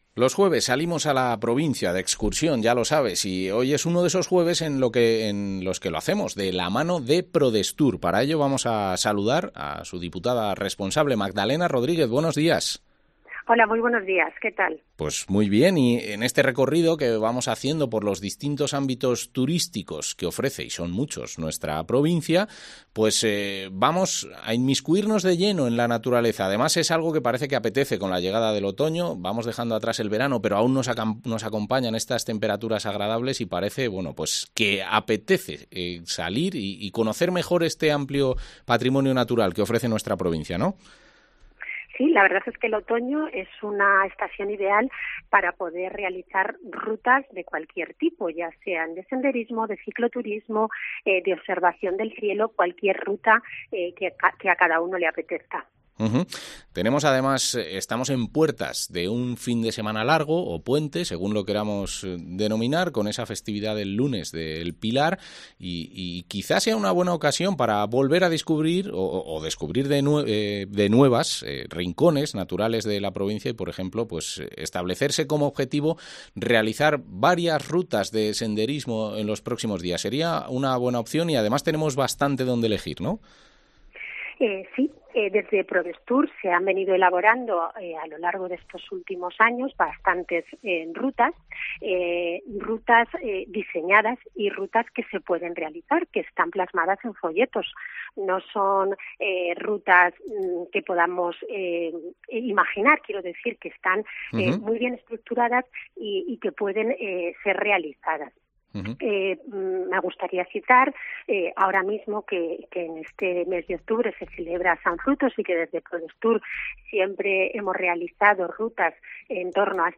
AUDIO: La diputada de Prodestur, Magdalena Rodríguez, detalla las posibilidades de la provincia en esos ámbitos